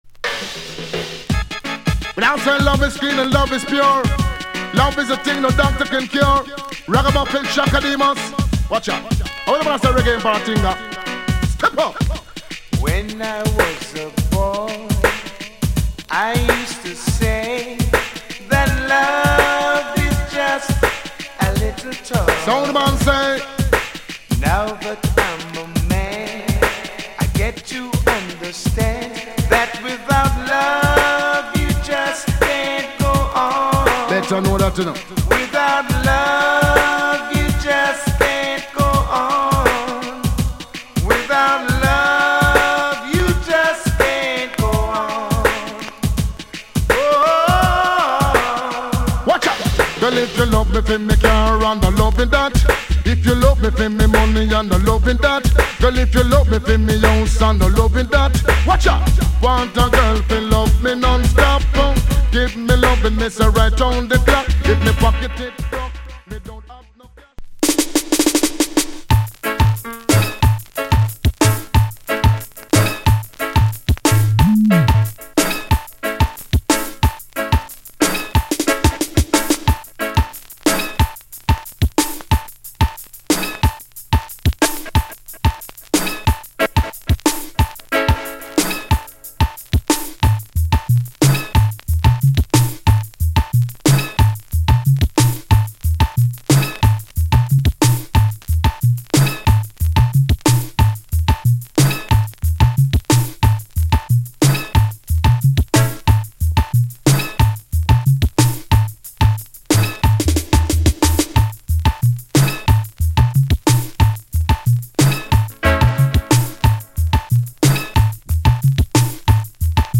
カバー+DJ Good Combination!!